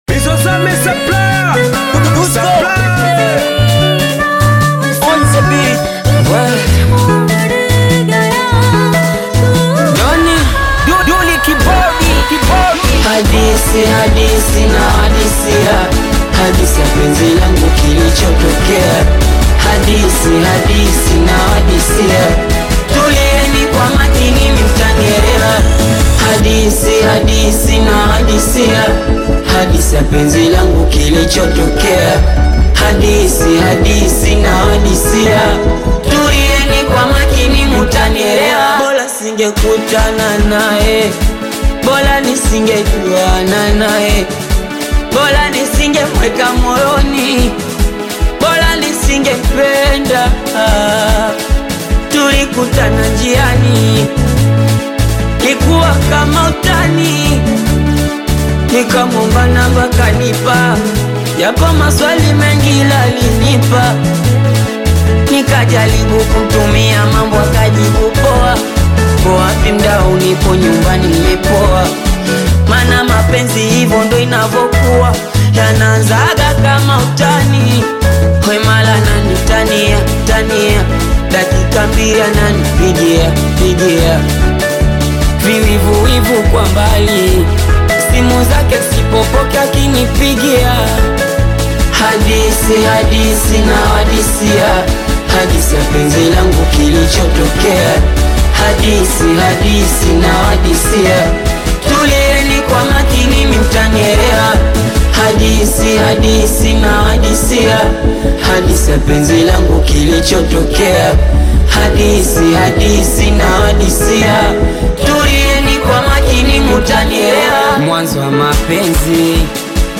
2026 Genre: Singeli / Afrobeat fusion Language